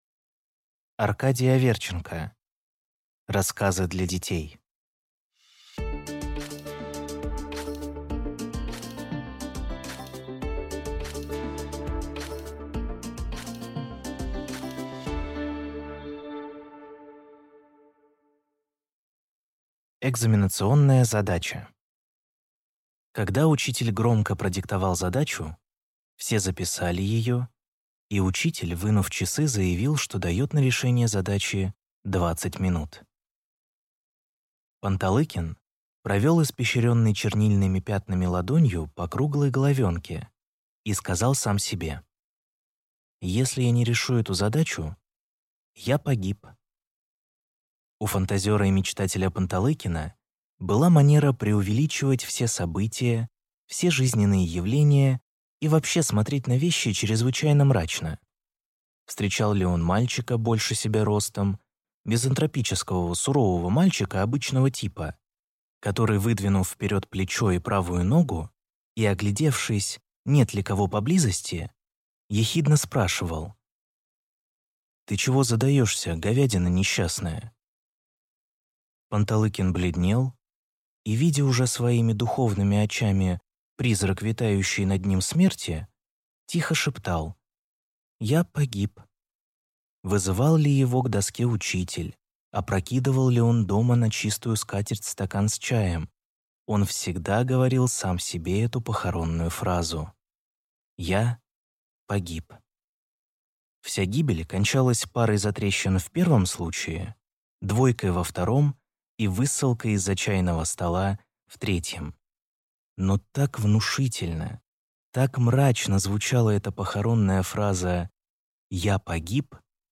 Аудиокнига Рассказы для детей | Библиотека аудиокниг